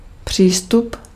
Ääntäminen
Synonyymit méthode préparation abordage voisinage rapprochage Ääntäminen France: IPA: /a.pʁɔʃ/ Haettu sana löytyi näillä lähdekielillä: ranska Käännös Ääninäyte Substantiivit 1. přístup {m} Suku: f .